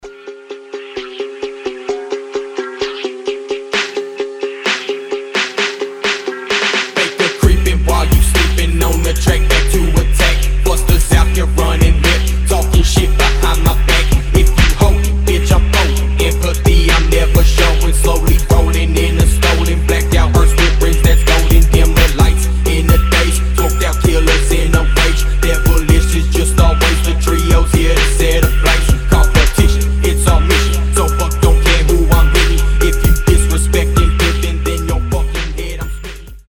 • Качество: 320, Stereo
качающие
фонк